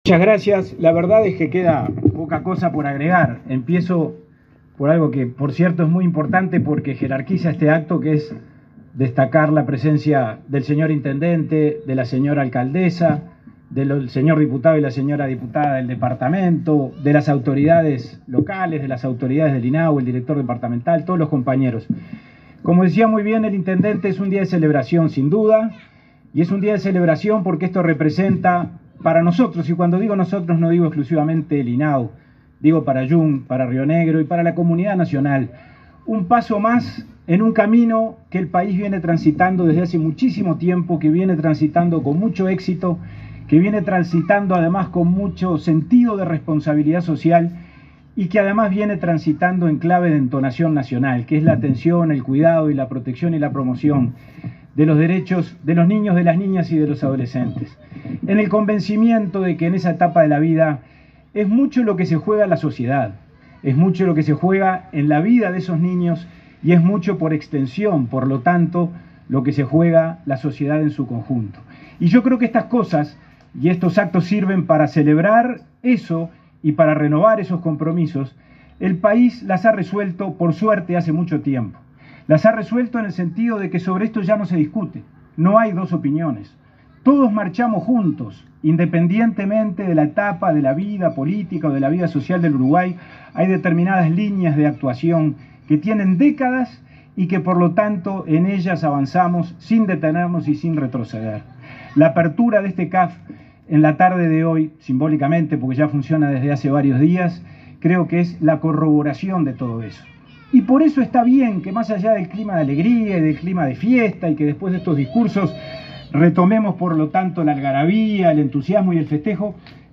Palabras del presidente de INAU, Pablo Abdala